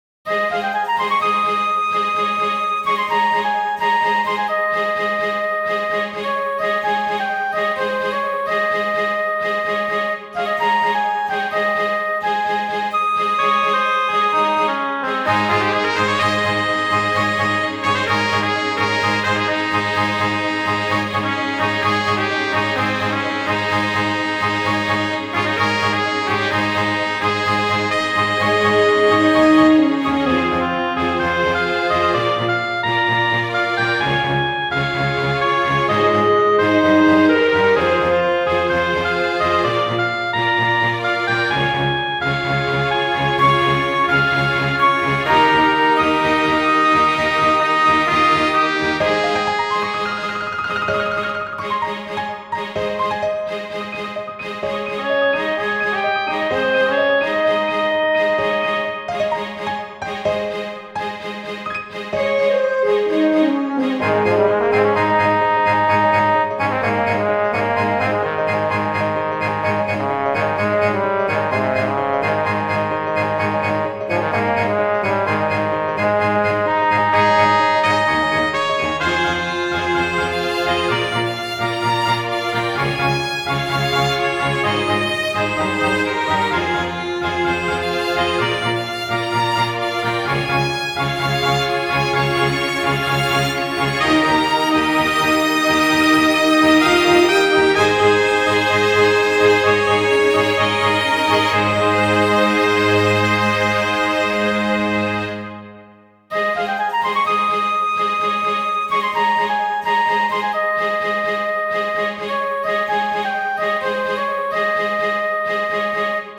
• 軽快なロックや慌てたものなどアップテンポな楽曲のフリー音源を公開しています。
ogg(L) 飛翔 さわやか オーケストラ
瑞々しく爽快なオーケストラ。